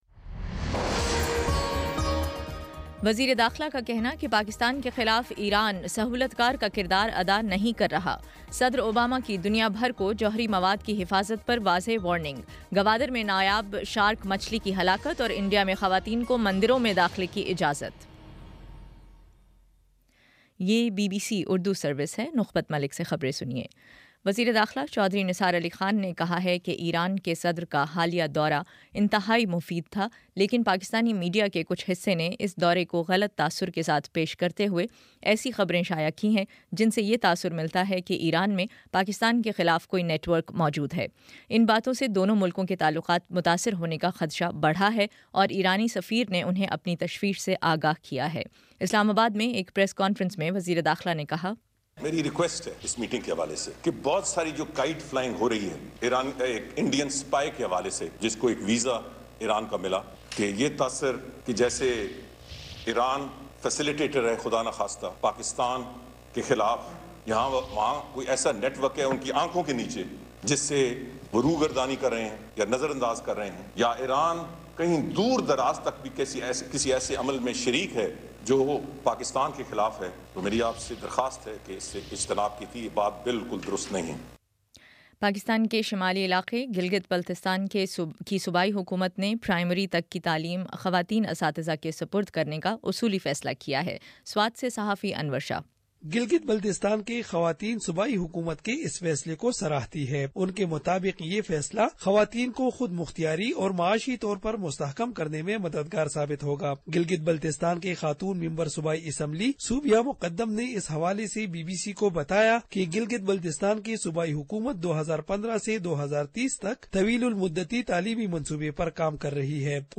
اپریل 02 : شام چھ بجے کا نیوز بُلیٹن